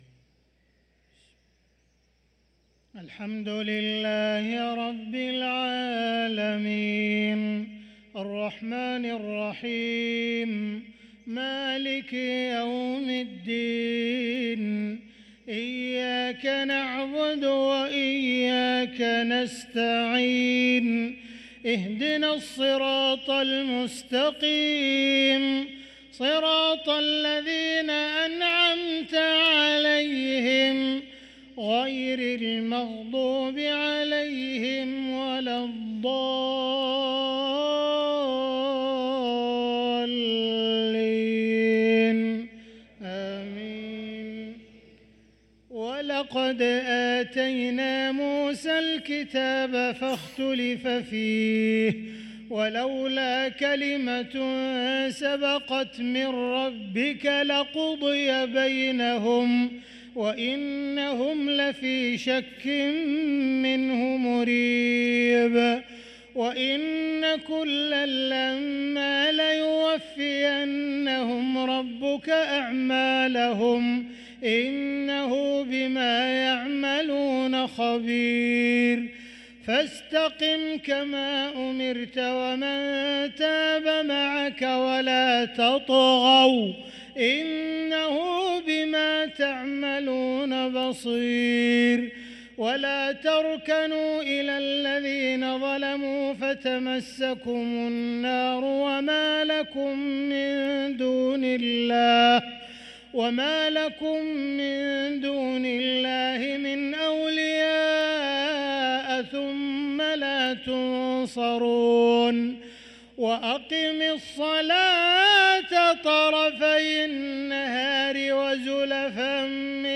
صلاة العشاء للقارئ عبدالرحمن السديس 10 رجب 1445 هـ